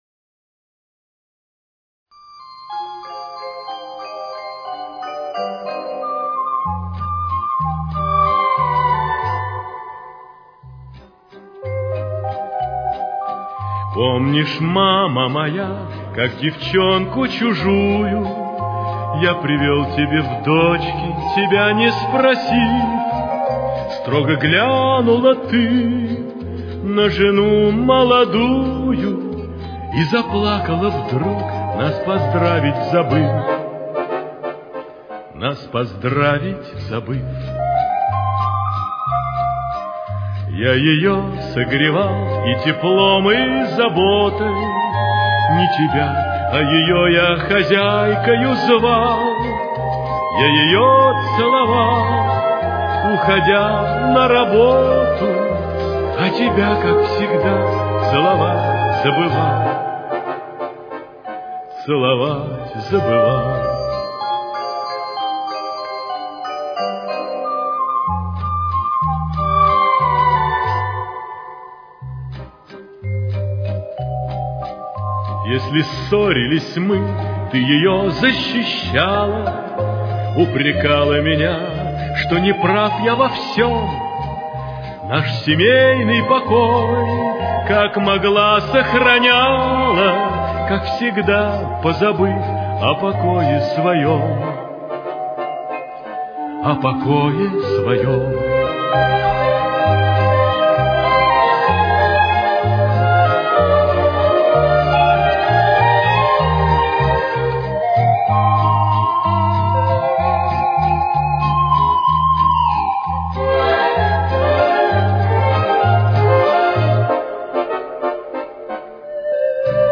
Темп: 190.